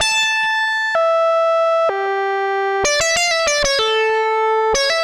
Index of /musicradar/80s-heat-samples/95bpm